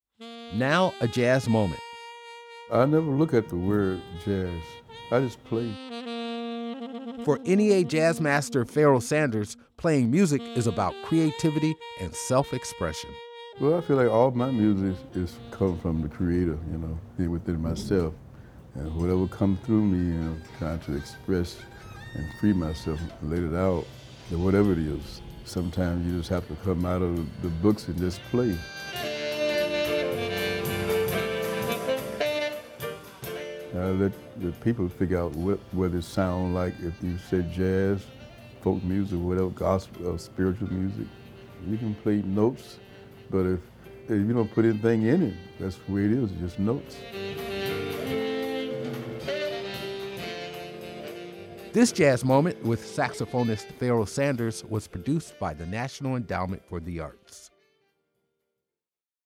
THIS JAZZ MOMENT WITH SAXOPHONIST PHAROAH SANDERS WAS PRODUCED BY THE NATIONAL ENDOWMENT FOR THE ARTS
Excerpt of “The Bird Song” composed and performed by Pharoah Sanders from the album, Welcome to Love, used courtesy of Timeless Records and excerpt of “You’ve Got to Have Freedom” performed live.